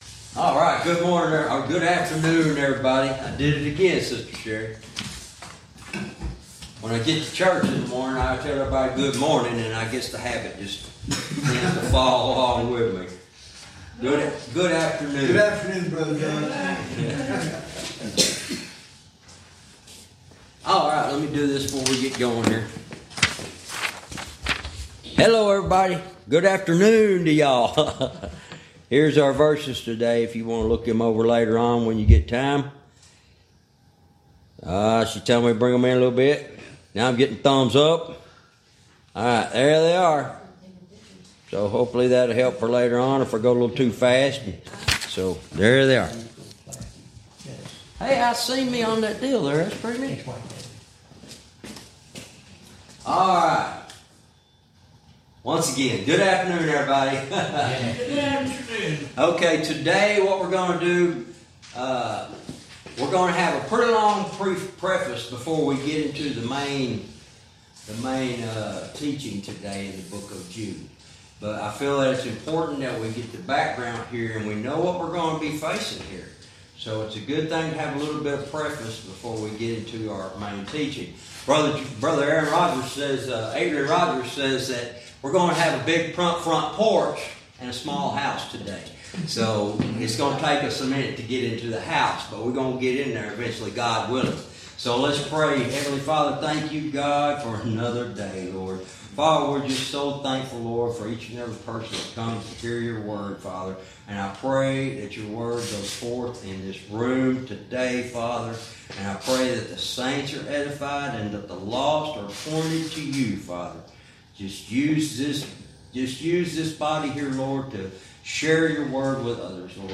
Verse by verse teaching - Jude Lesson 60 Verse 14